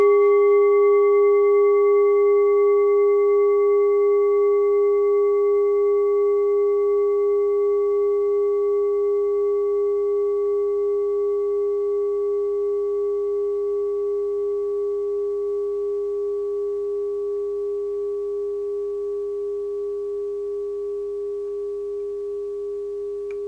Kleine Klangschale Nr.10 Bengalen Planetentonschale
Kleine Klangschale Nr.10
Klangschale-Durchmesser: 11,9cm
Diese Klangschale ist eine Handarbeit aus Bengalen. Sie ist neu und ist gezielt nach altem 7-Metalle-Rezept in Handarbeit gezogen und gehämmert worden.
kleine-klangschale-10.wav